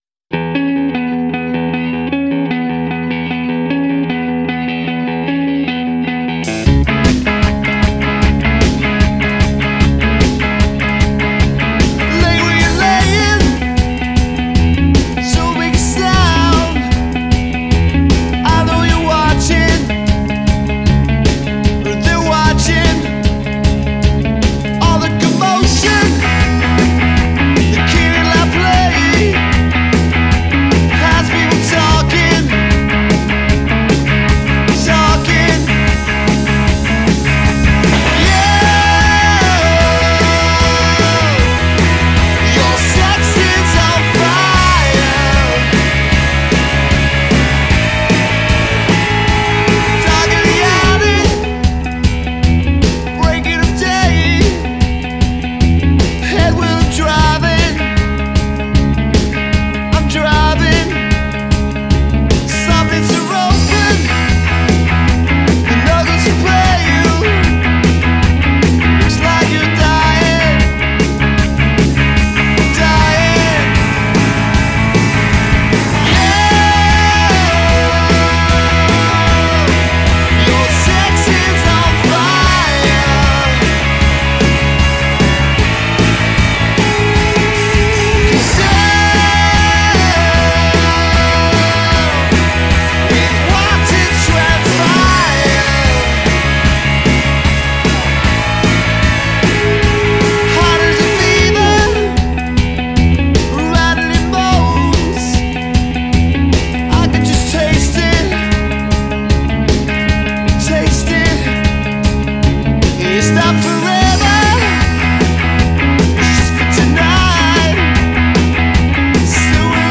• Professional, highly-talented pop cover band
• Guitar-based 5-piece, great harmony vocals
lead vocals and guitar
drums and backing vocals
percussion and backing vocals
bass guitar and vocals